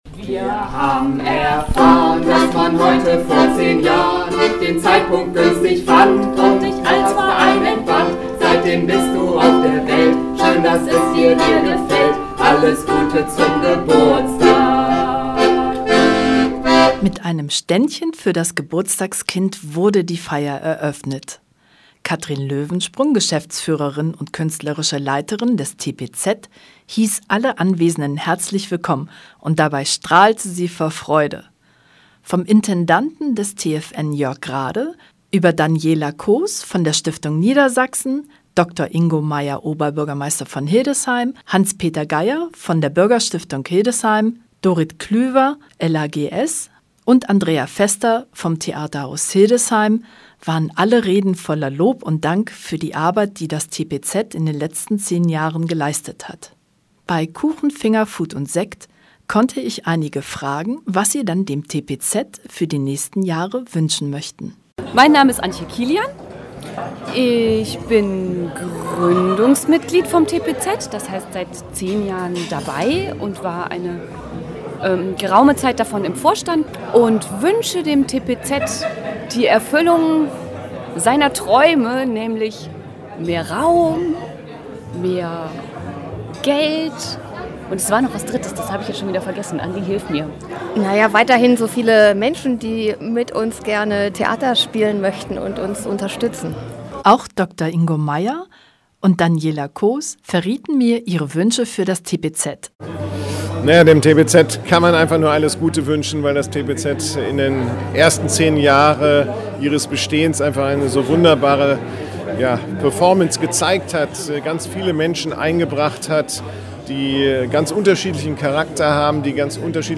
10 Jahre TPZ: • Bericht Radio Tonkuhle
10-Jahre-TPZ-Hildesheim-Bericht-Radio-Tonkuhle.mp3